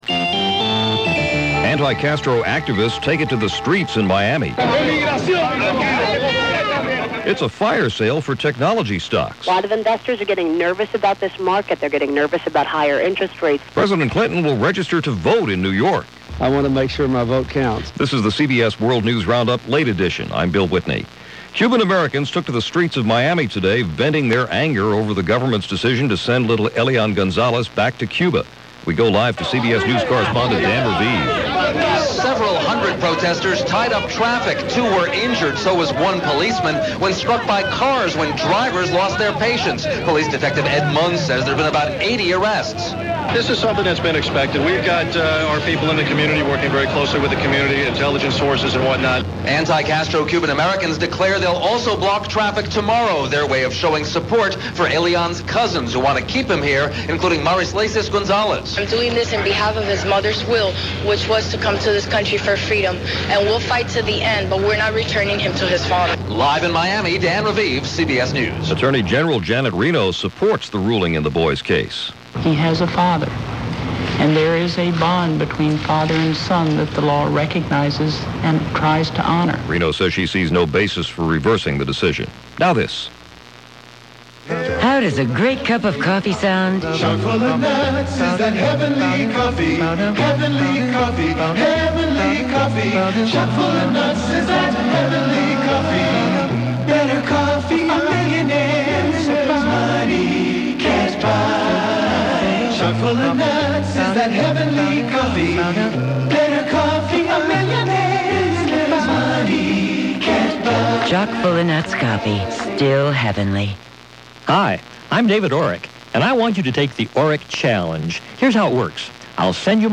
And while the drama over Elian Gonzalez continued, that’s just a little of what happened, this January 6, 2000 as reported by The CBS World News Roundup Late Edition.